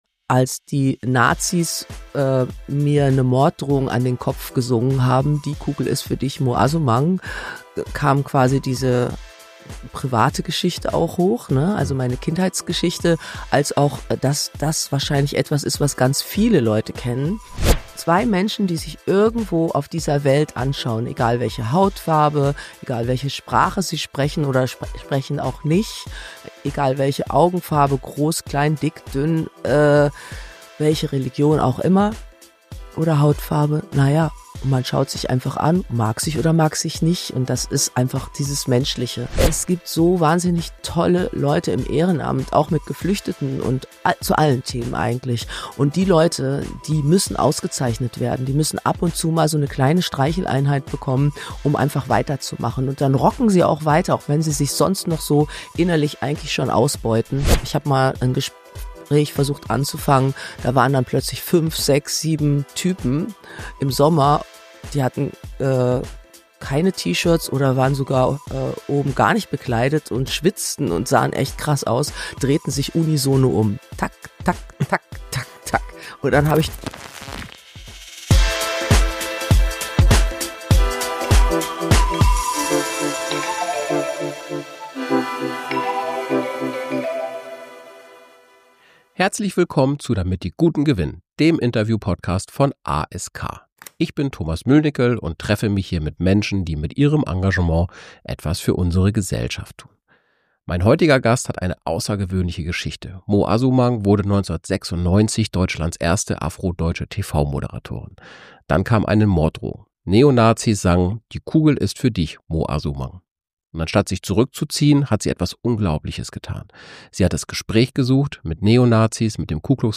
Was jede*r tun kann – und warum Wegschauen keine Option ist Ein bewegendes Gespräch über Mut, Identität und die Kunst, menschlich zu bleiben.